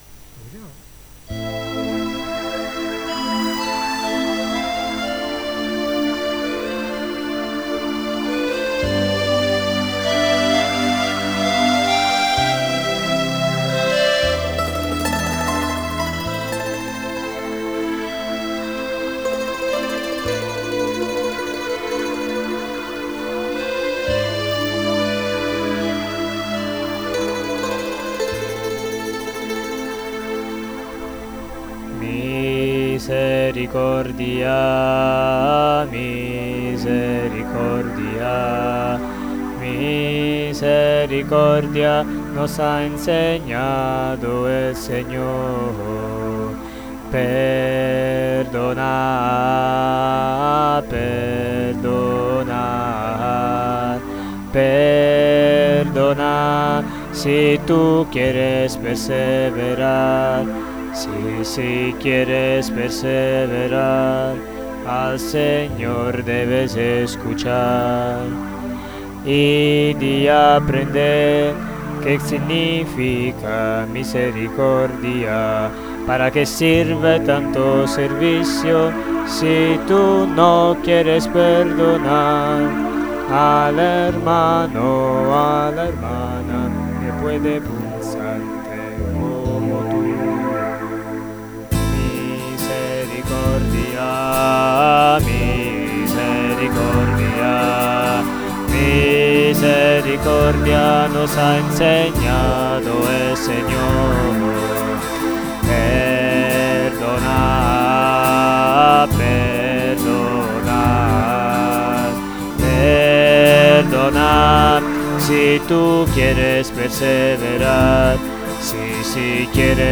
notas de guitarra